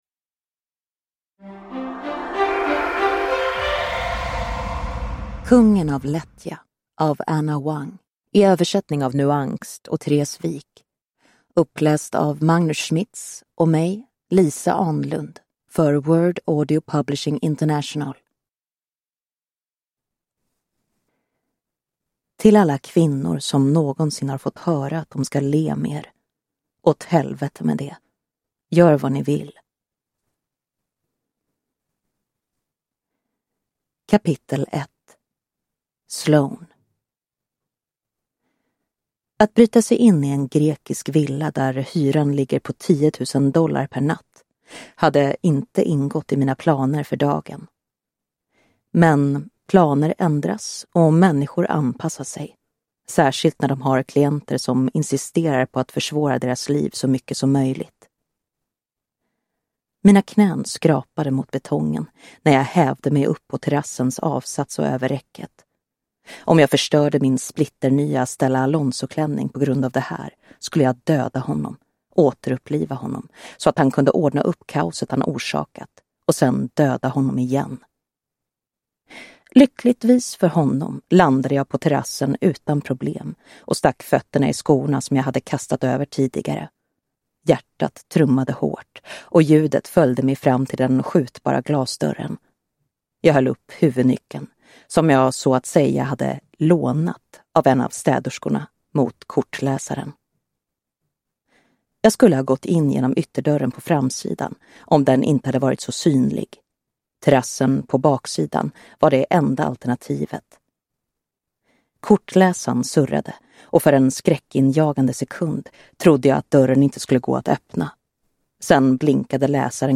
Uppläsare:
Ljudbok